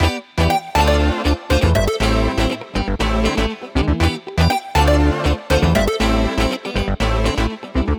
23 Backing PT1.wav